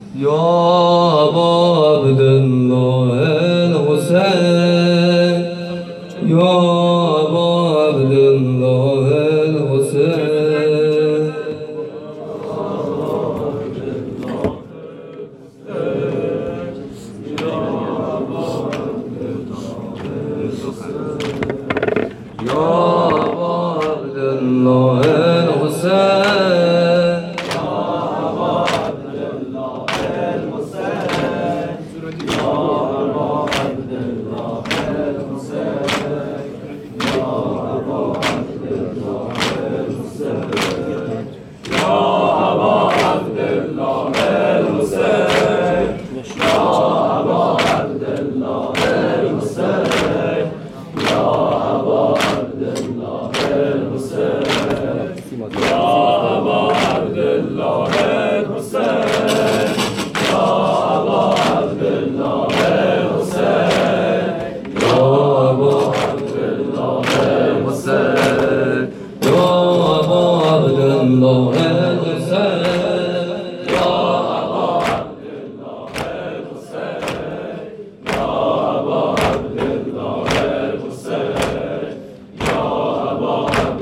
شب اول محرم ۹۷ هیئت صادقیون(ع)
2-ذکرورودبه-زمینه.mp3